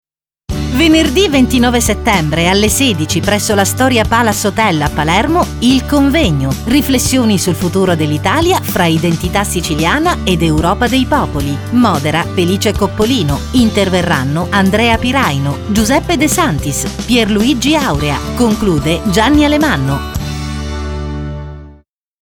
Naturelle, Polyvalente, Fiable, Mature, Douce
Commercial